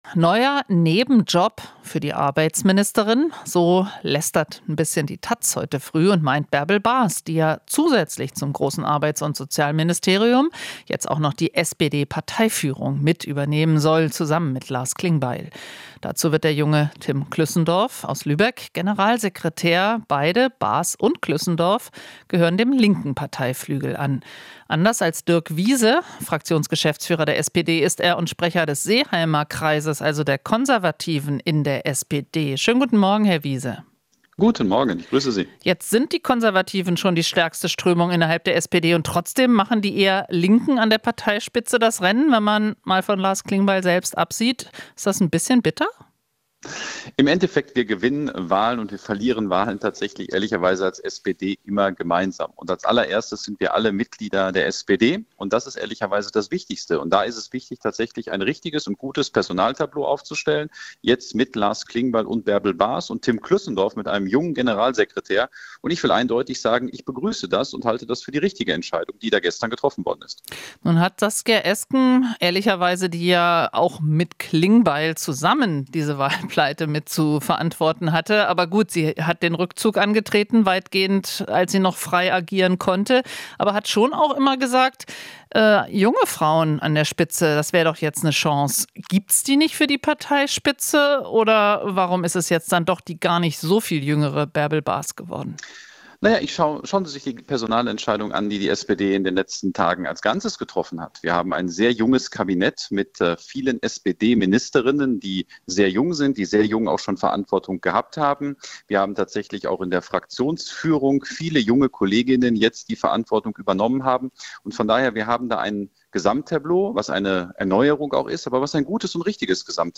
Interview - Wiese (SPD) begrüßt Bas und Klüssendorf im Personaltableau